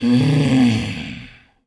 Звуки кряхтения
Ворчливое кряхтение бабули